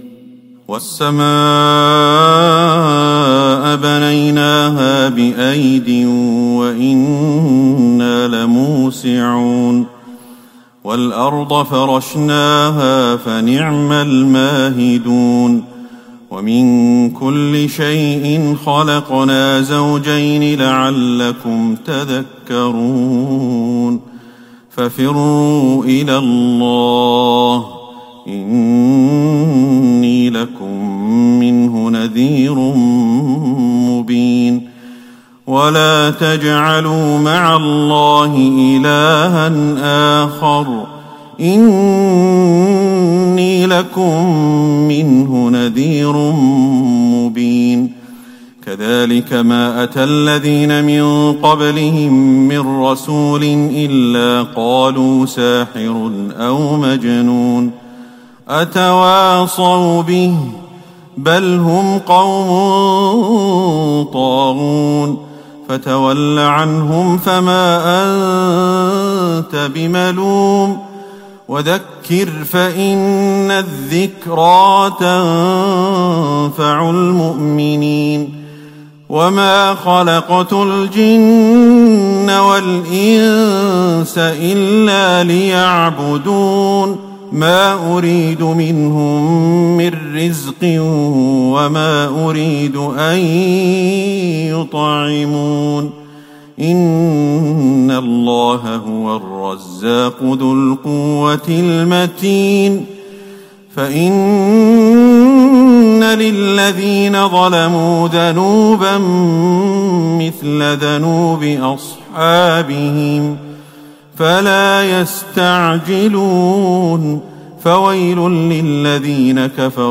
تهجد ٢٧ رمضان ١٤٤١هـ من سورة الذاريات ٤٧-النهاية والطور والنجم والقمر ١-٨ > تراويح الحرم النبوي عام 1441 🕌 > التراويح - تلاوات الحرمين